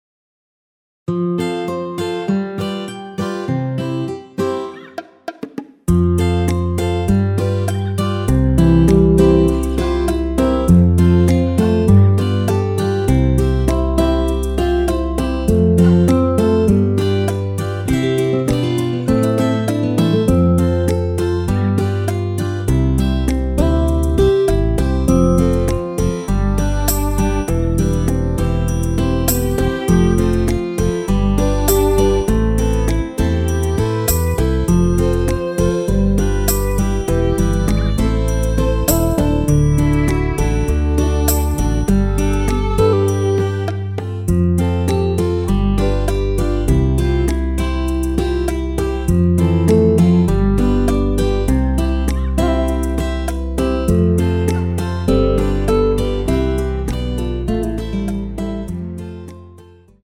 시작 부분 여자 파트 삭제, 바로 남자 파트로 시작 됩니다.(가사 참조)
원키에서(+6)올린 여자파트 삭제 MR입니다.
앞부분30초, 뒷부분30초씩 편집해서 올려 드리고 있습니다.
중간에 음이 끈어지고 다시 나오는 이유는